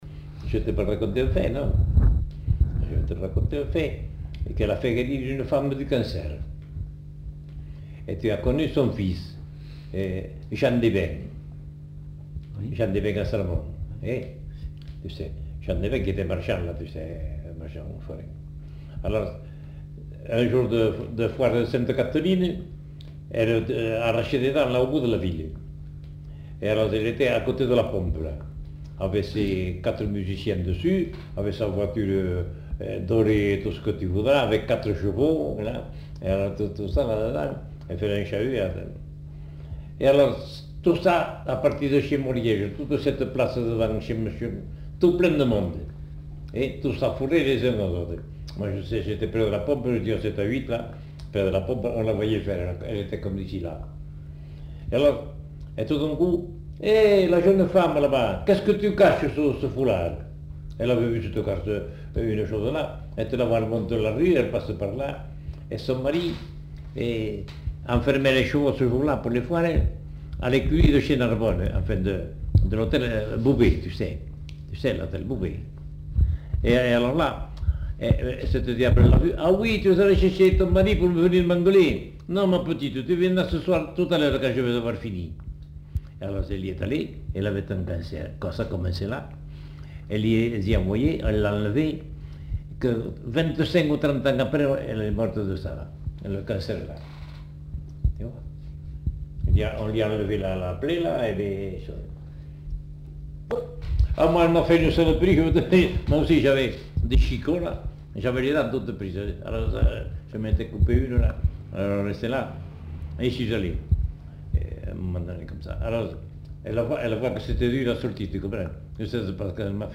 Lieu : Lombez
Genre : témoignage thématique